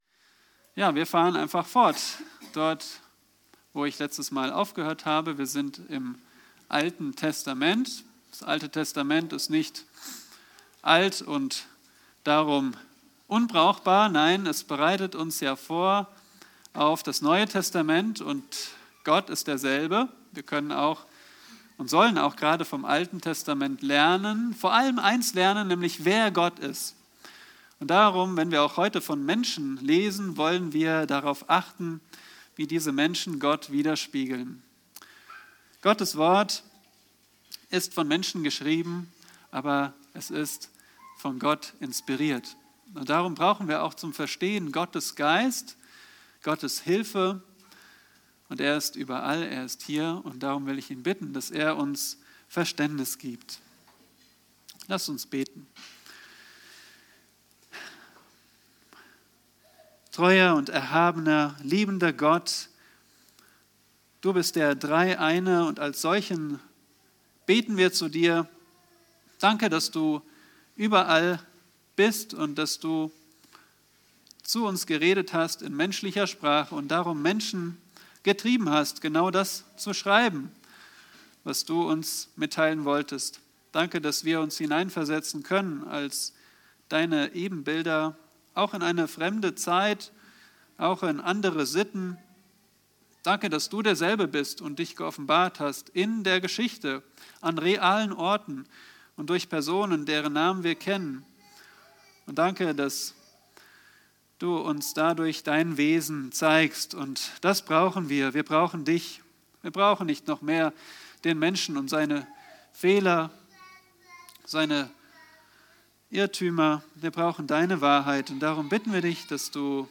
Eine predigt aus der serie "Das Buch Ruth."